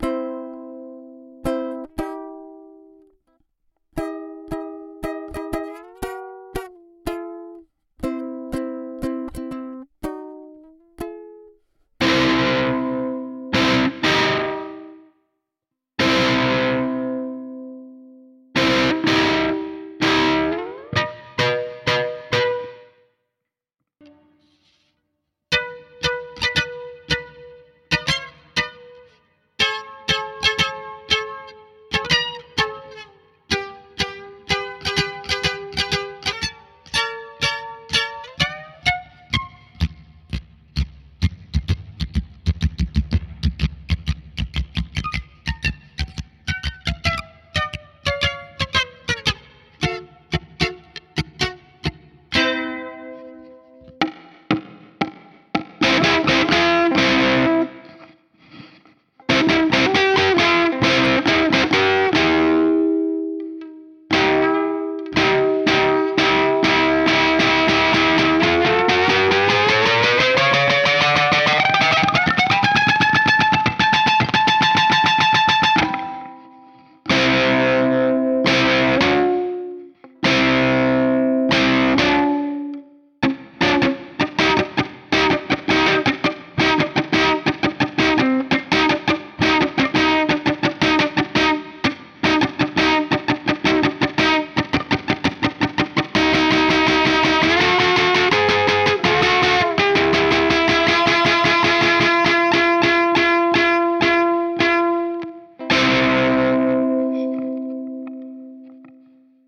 Yeah, uhhm, I wanted a little guitarish thing to play powerchords on.
Ukulele strings, if you buy a 4 pack of ukulelestrings I used the fattest
I’ve tuned them to C, E, C (one octave higher) to play them powerchords.
The pickups are piezo discs connected in serial to increase the volume.